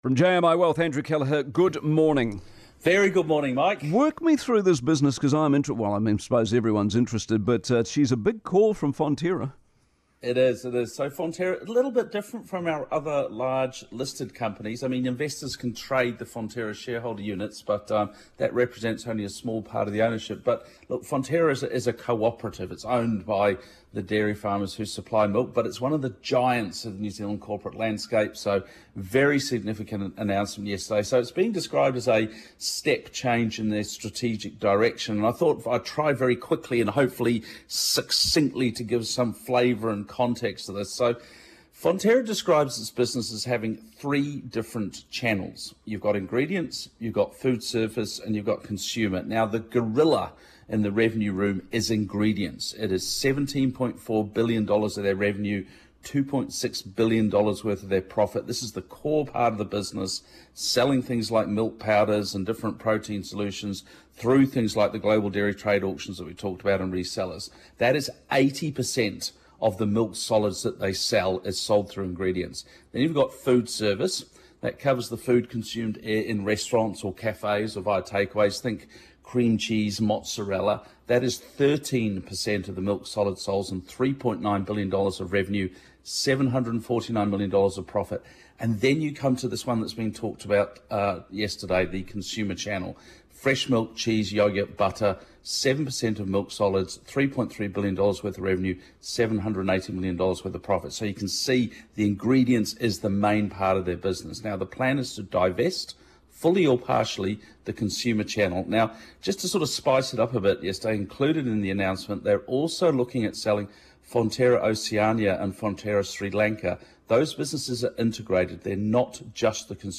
Newstalk ZB Commentary | Select Wealth